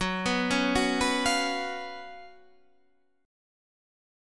F#M7sus4 chord